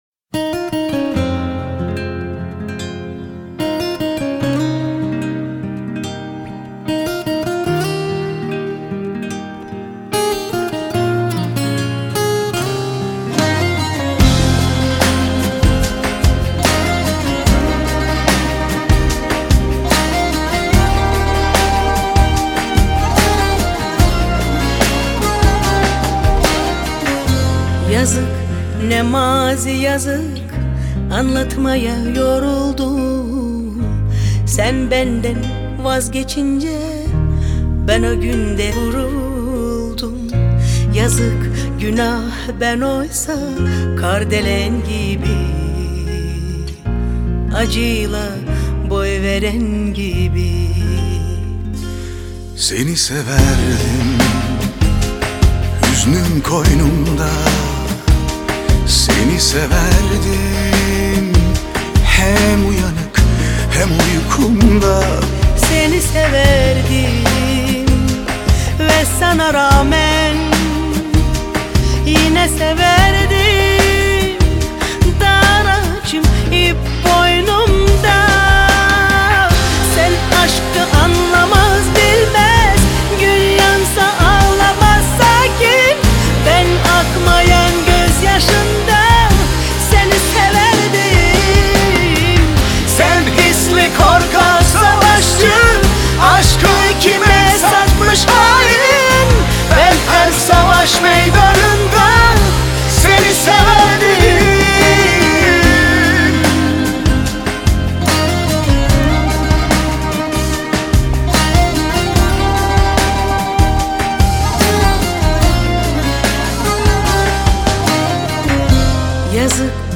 это трек в жанре узбекской поп-музыки